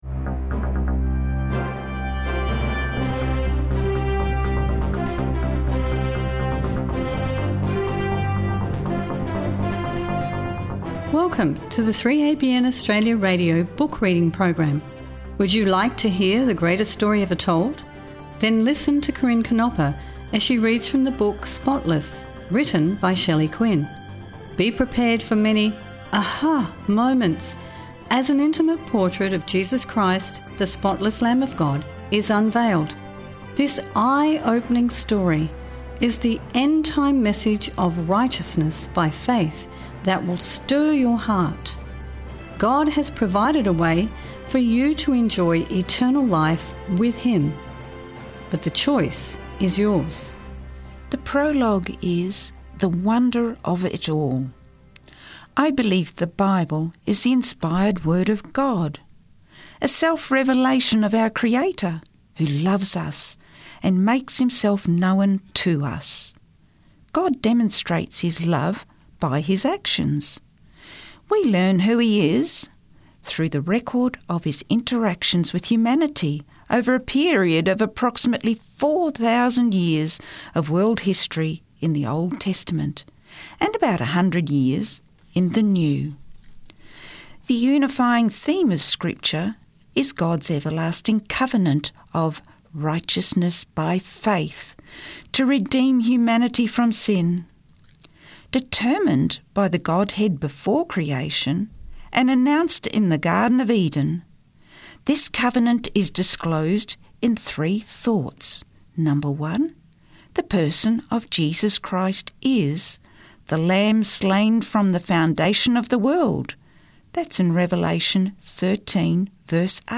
Book Reading